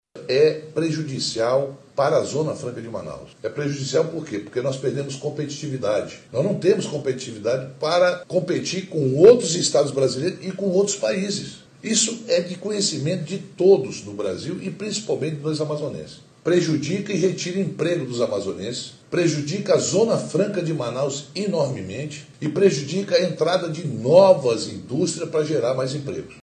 O senador Omar Aziz destacou que a mudança diminui a competitividade comercial da Zona Franca, e pode gerar, inclusive, desemprego.
SONORA-OMAR-AZIZ.mp3